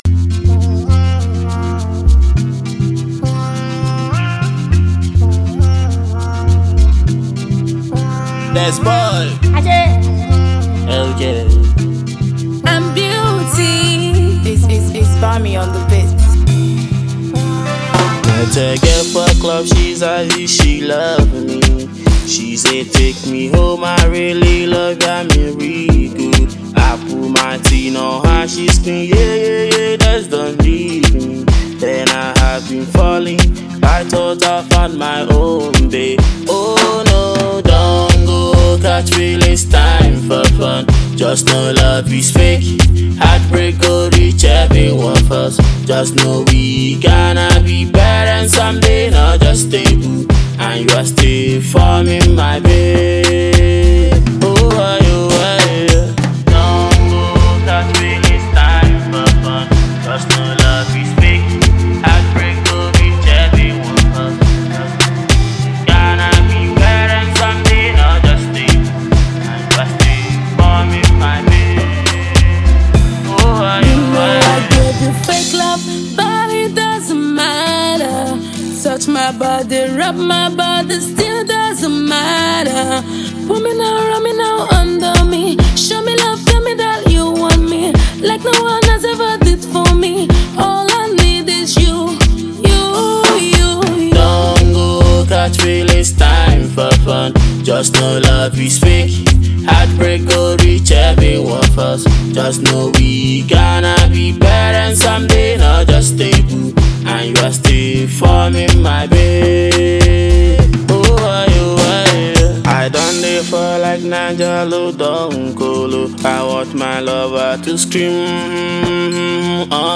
The super talented singer/rapper
studio piece